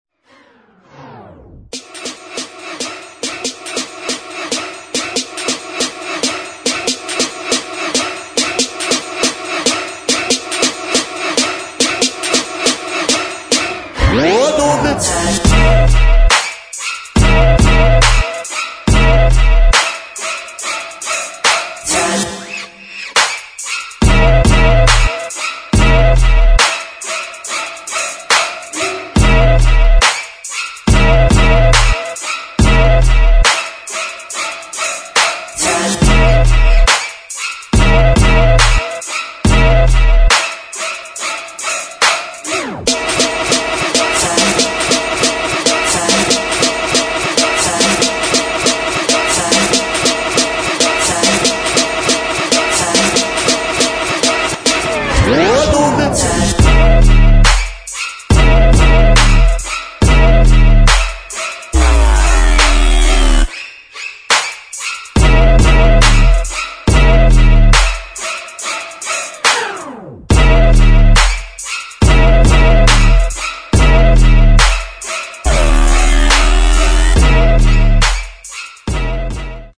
[ GRIME ]